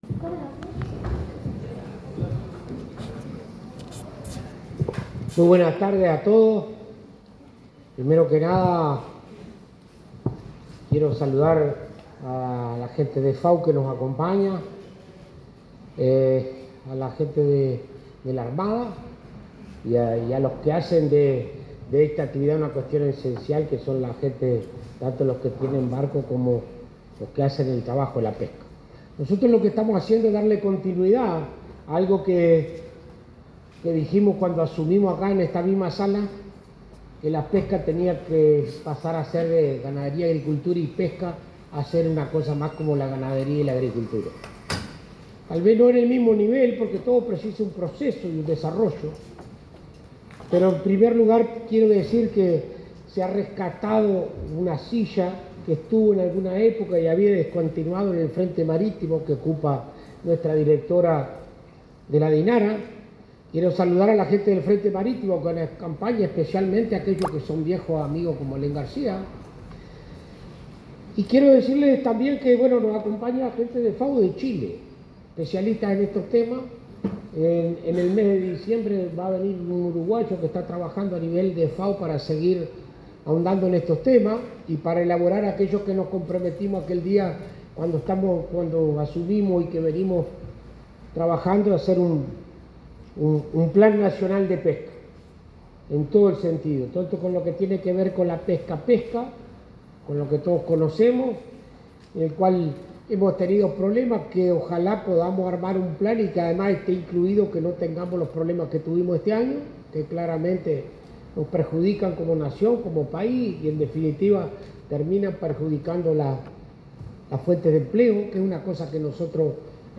Palabras del ministro de Ganadería, Agricultura y Pesca, Alfredo Fratti
Palabras del ministro de Ganadería, Agricultura y Pesca, Alfredo Fratti 21/11/2025 Compartir Facebook X Copiar enlace WhatsApp LinkedIn En la celebración del Día Mundial de la Pesca, que se desarrolló este viernes 21, se expresó el ministro de Ganadería, Agricultura y Pesca, Alfredo Fratti.